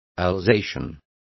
Complete with pronunciation of the translation of alsatians.